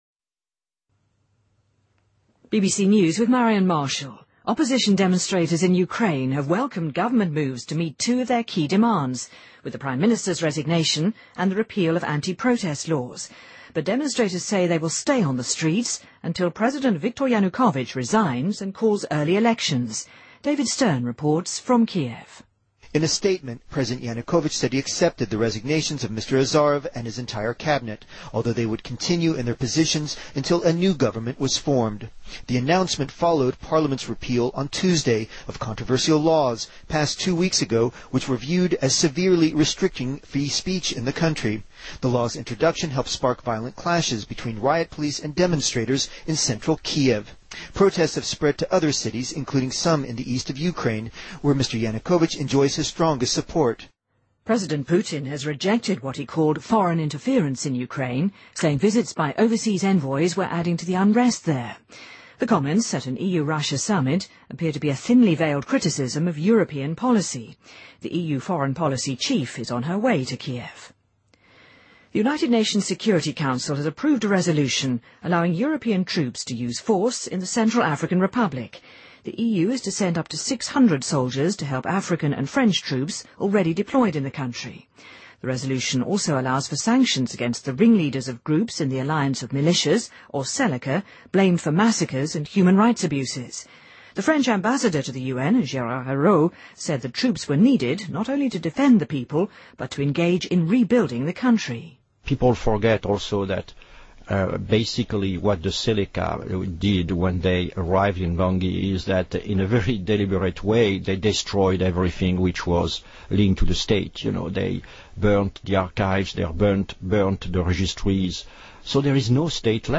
BBC news,2014-01-29